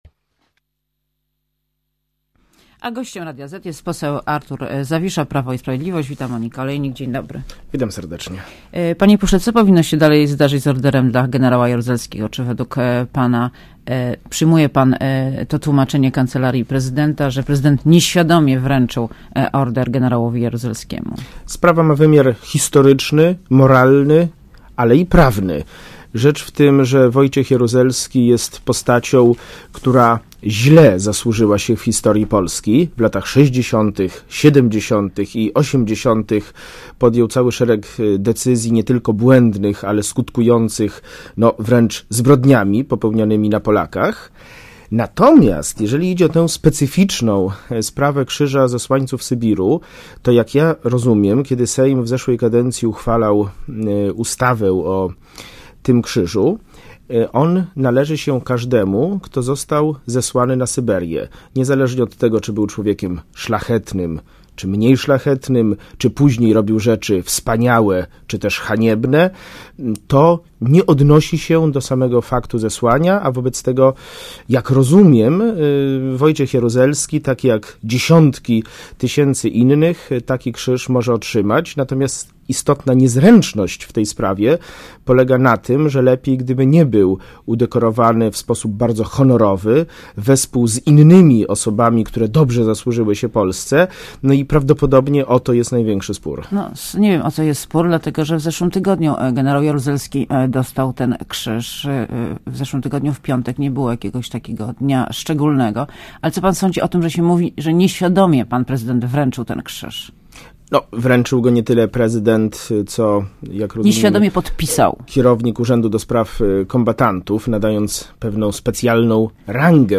Posłuchaj wywiadu Gościem Radia ZET jest poseł Artur Zawisza , Prawo i Sprawiedliwość.